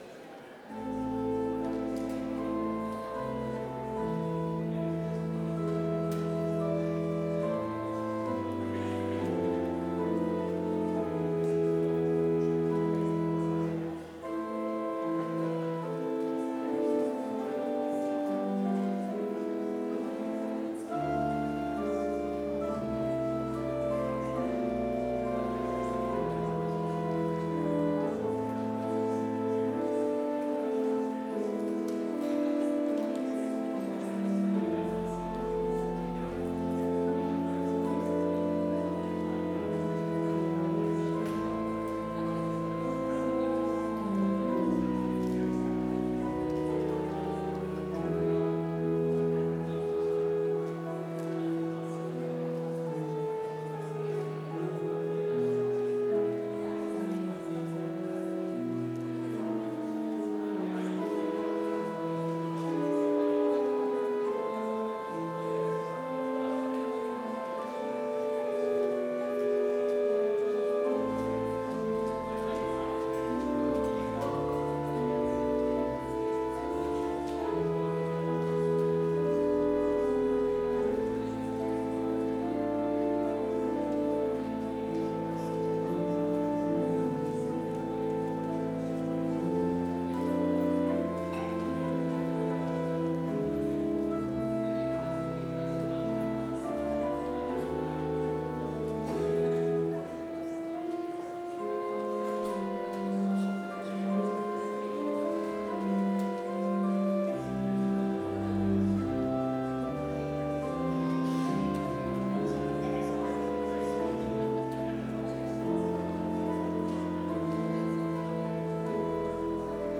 Lent service held on April 9, 2025, in Trinity Chapel
choral setting
Complete service audio for Lent - Wednesday, April 9, 2025